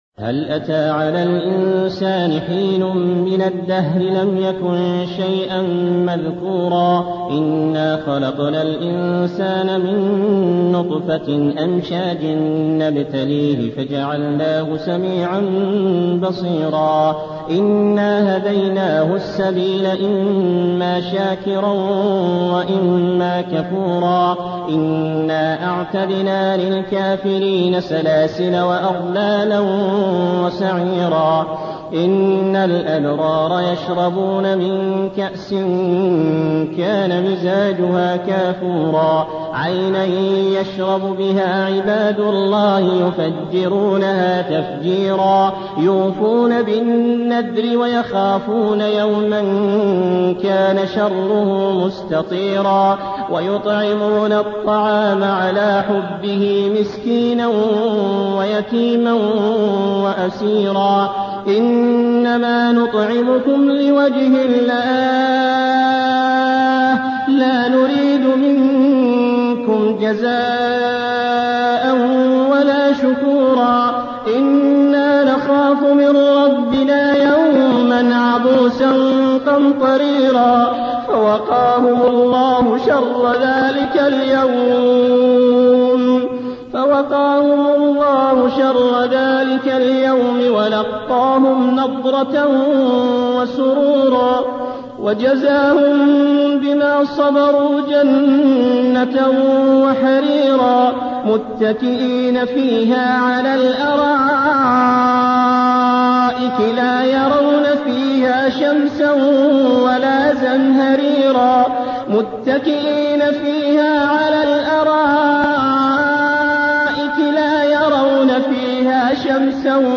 سورة الإنسان بصوت ثلاثة قراء